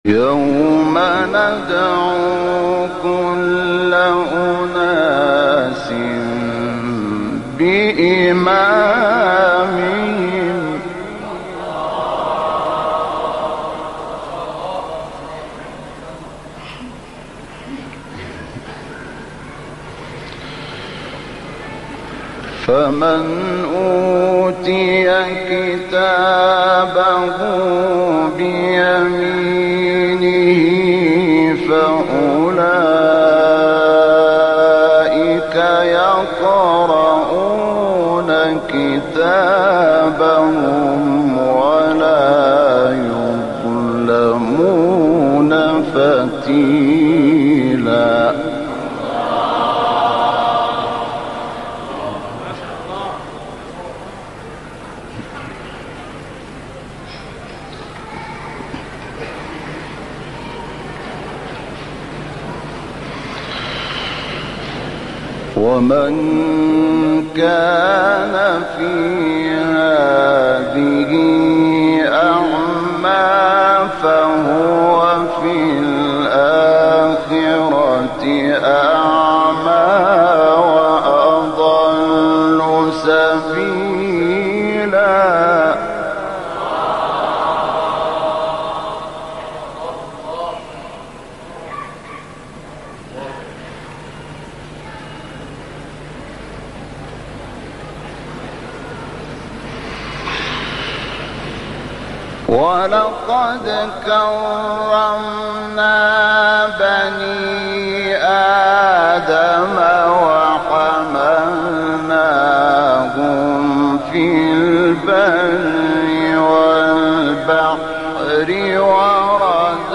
ماشاء الله علي الصوت 🥰🥰